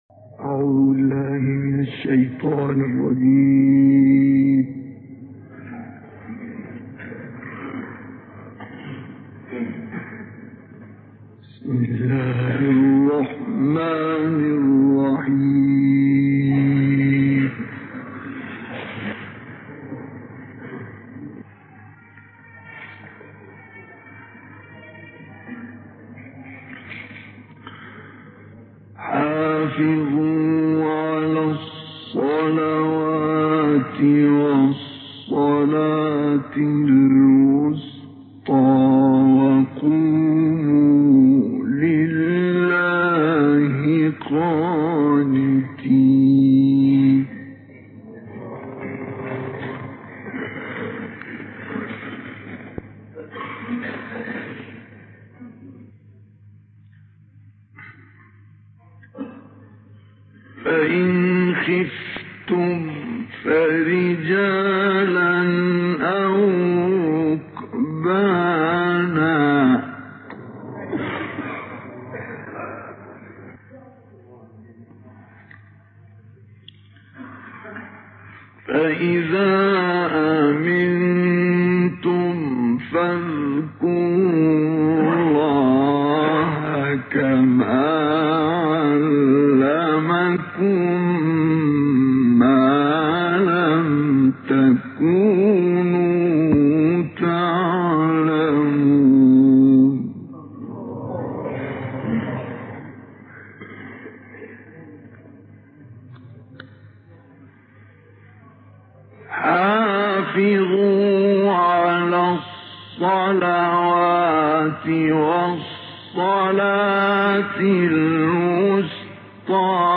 این تلاوت در سال 1957 میلادی در مسجد جامع اموی شهر حلب سوریه اجرا شده و مدت زمان آن 33 دقیقه است.